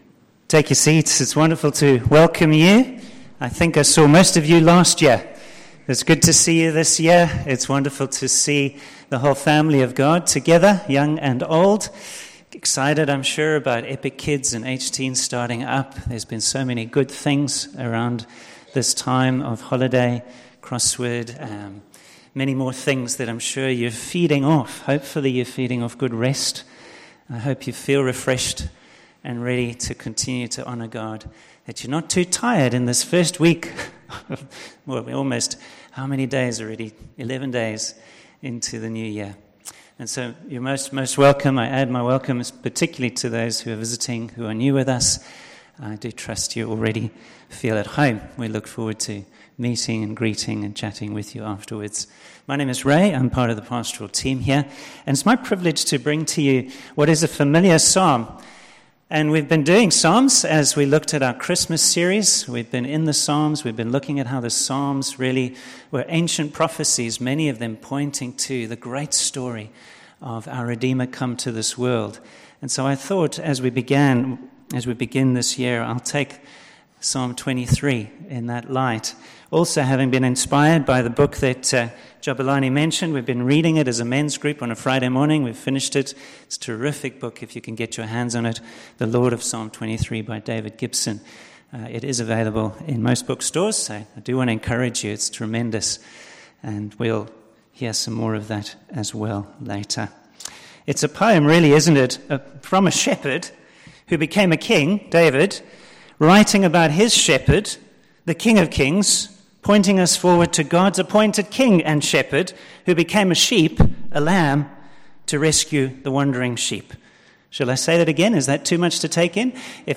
Psalm 23 Service Type: Morning Service Psalm 23 and the good shepherd